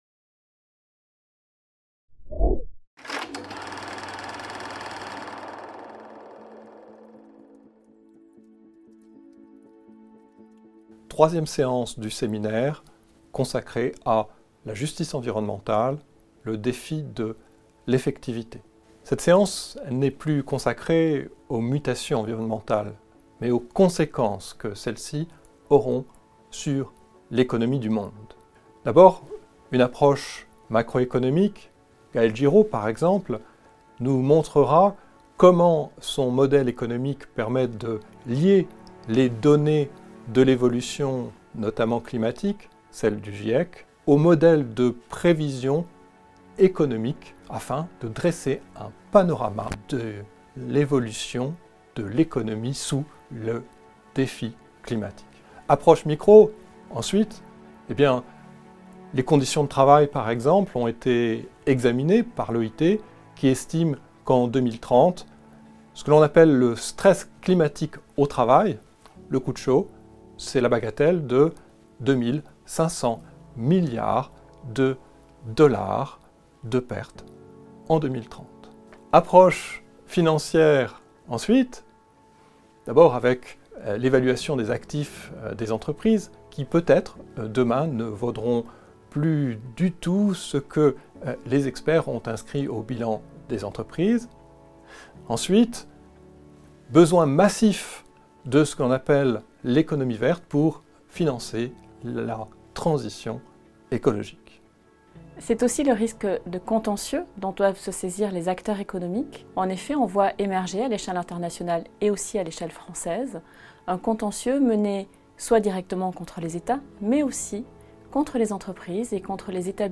Justice environnementale, le défi de l'effectivité - Conférence 3 | Canal U
Programme 2020/2021 du cycle bi-annuel de conférences à la Cour de cassation